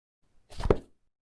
Звуки книги
Закрыли книгу